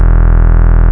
72.10 BASS.wav